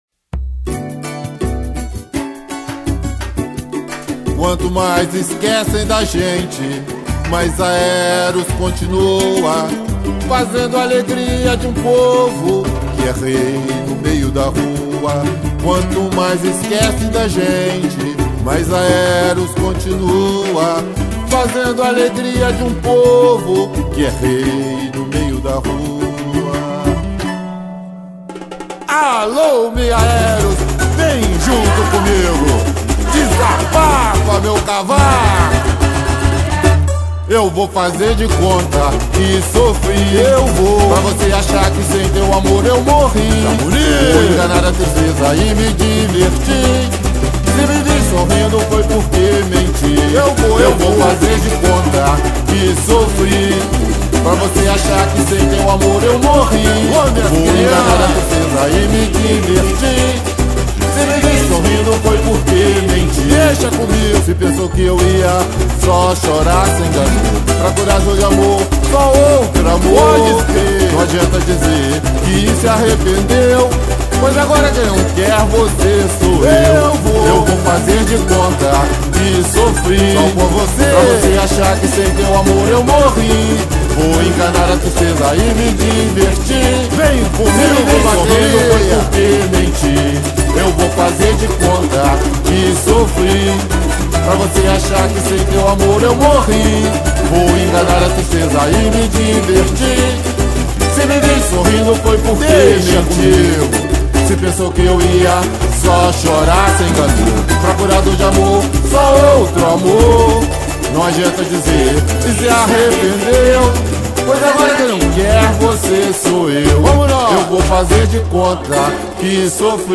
apresentou o samba-enredo 2019